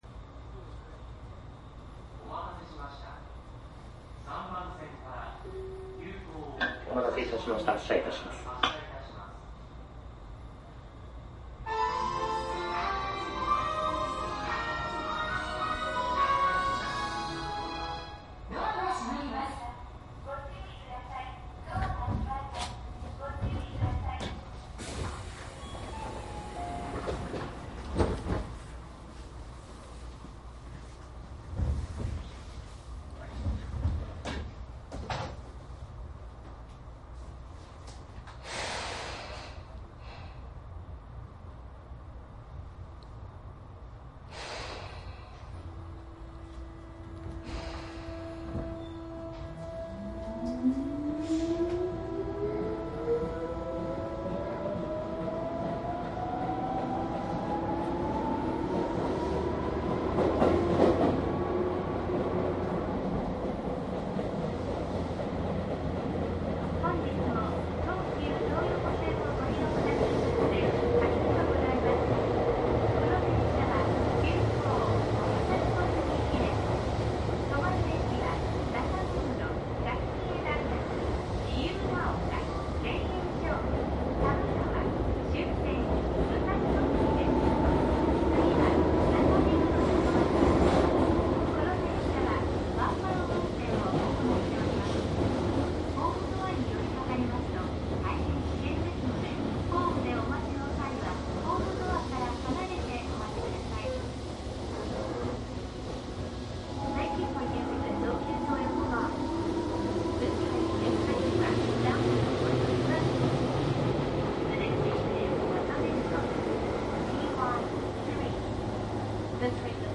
東武9000系   副都心線 走行音CD
いずれもマイクECM959です。DATかMDの通常SPモードで録音。
実際に乗客が居る車内で録音しています。貸切ではありませんので乗客の会話やが全くないわけではありません。